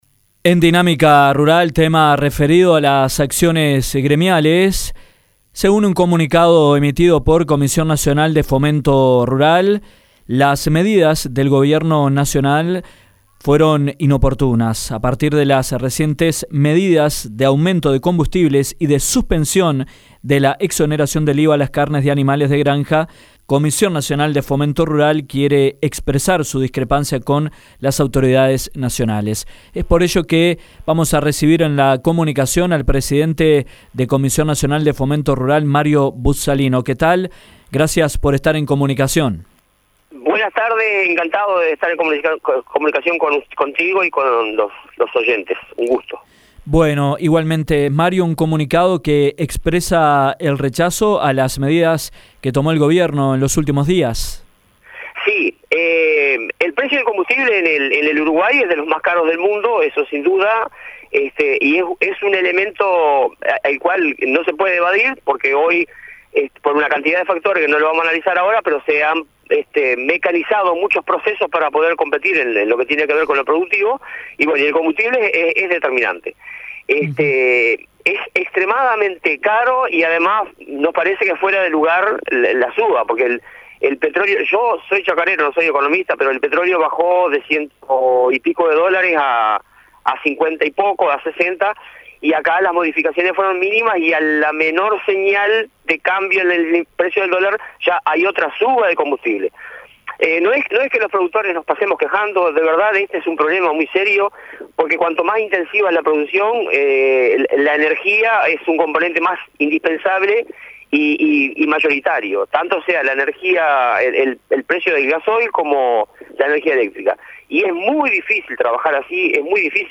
en diálogo con Dinámica Rural